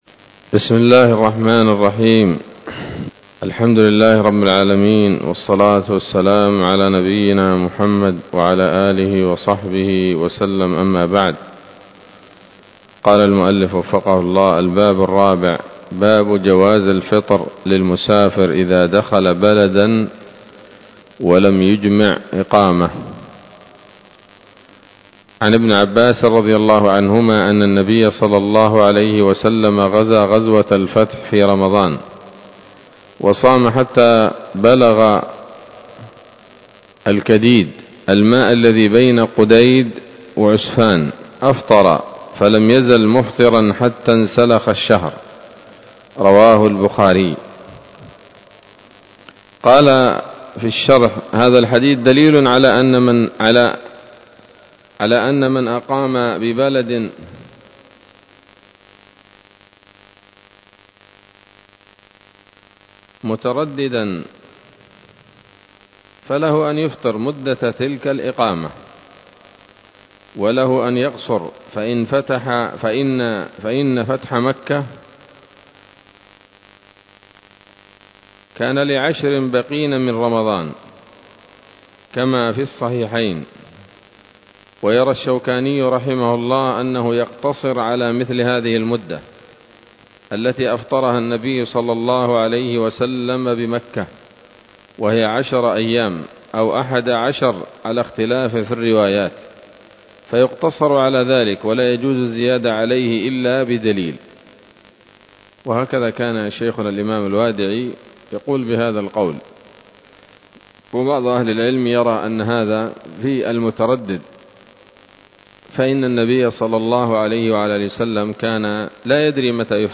الدرس السابع عشر من كتاب الصيام من نثر الأزهار في ترتيب وتهذيب واختصار نيل الأوطار